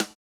MOO Snare 2.wav